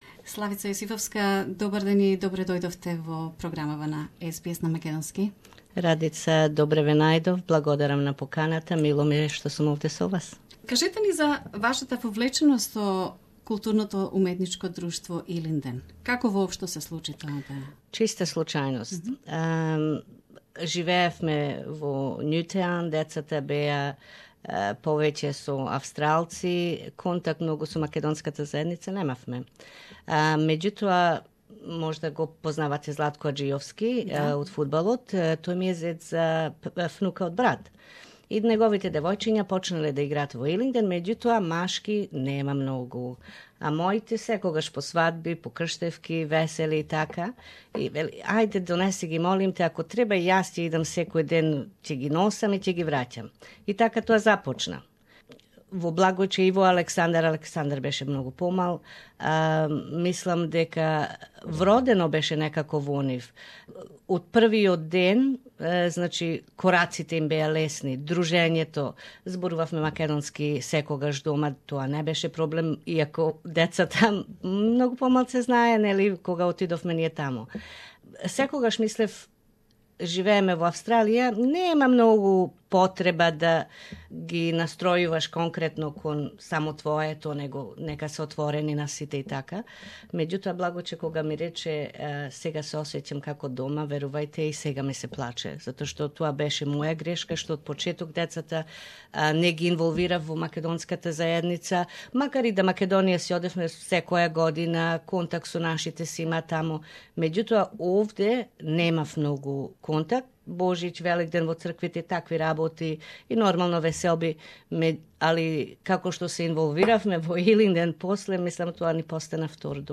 at SBS studio